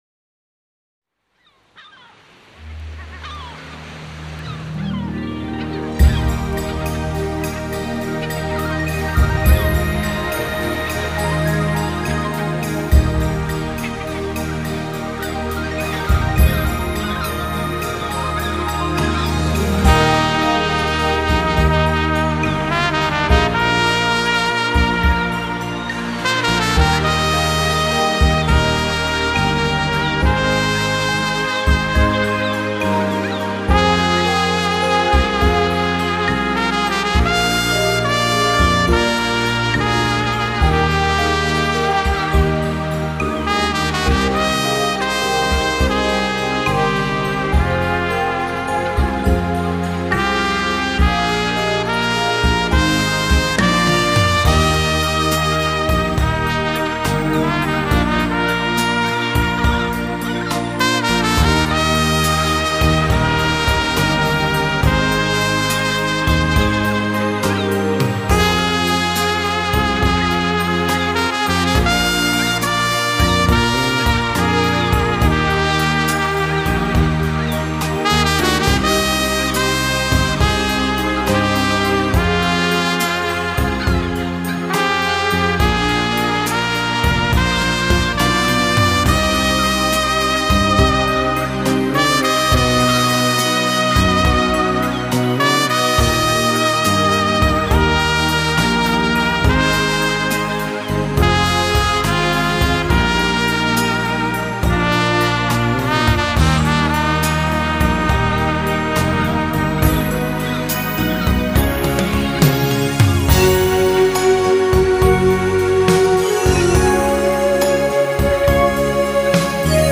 Format: Vinyl, 7", 45 RPM, Single
Genre: Jazz, Classical
Style: Easy Listening